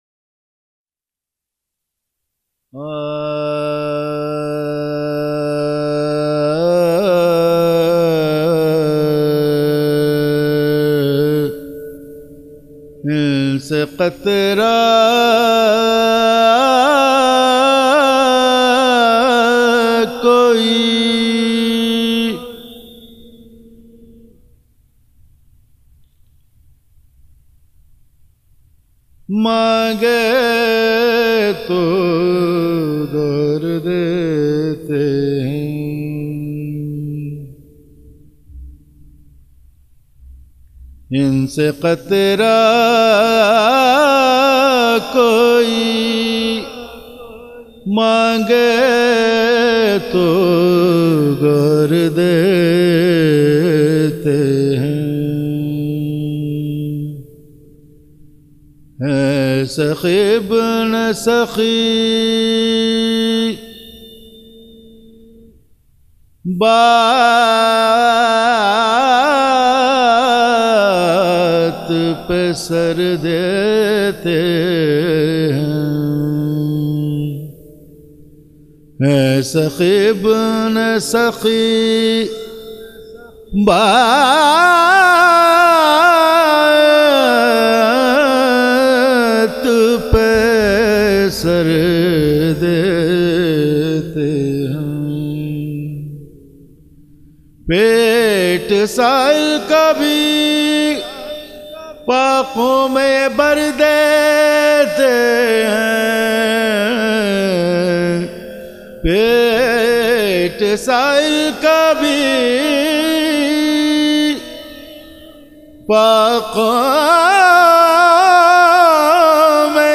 سوزوسلام اورمرثیے